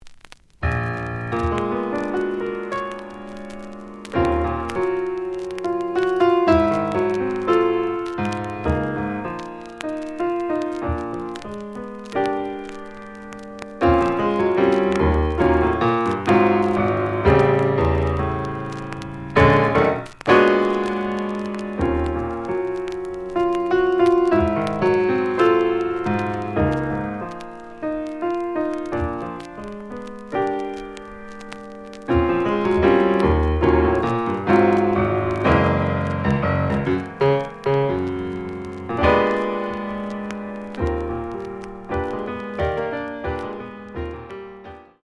The audio sample is recorded from the actual item.
●Genre: Jazz Funk / Soul Jazz
Some noise on both side labels.